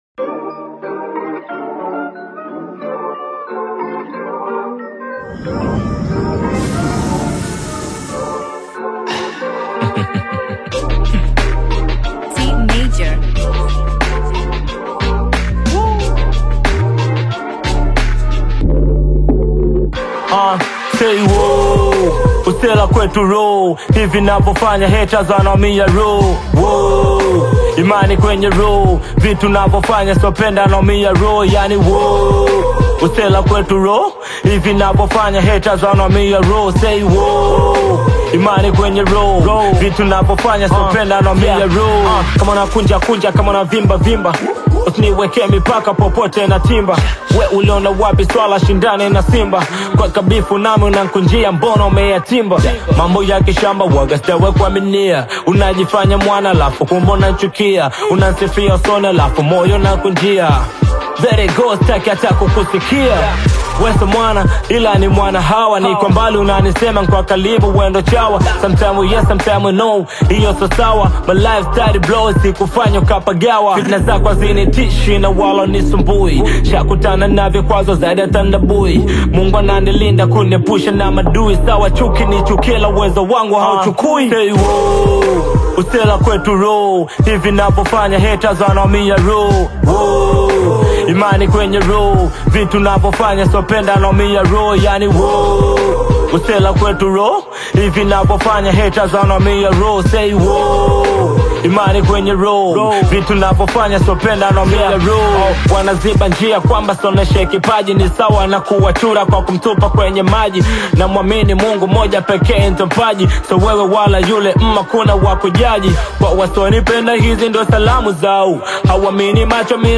AudioHip Hop
is an energetic hip-hop/Afro-inspired single